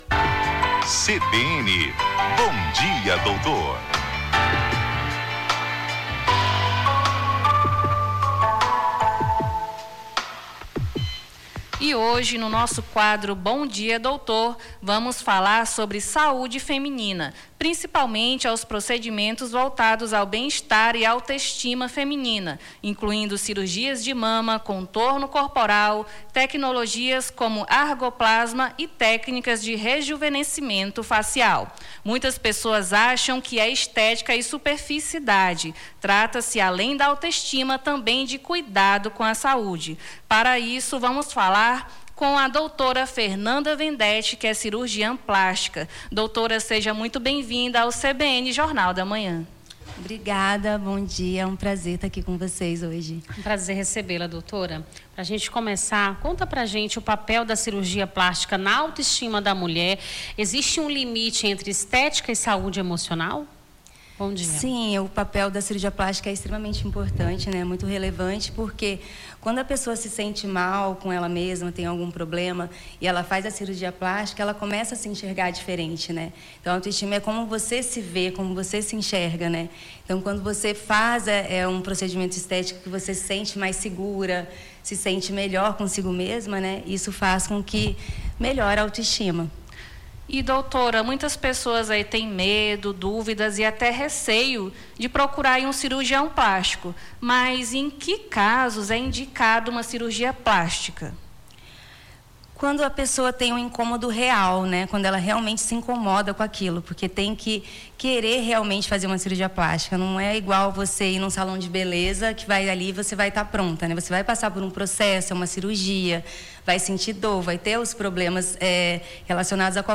Bom dia, Doutor: cirurgiã plástica esclarece dúvidas sobre procedimentos estéticos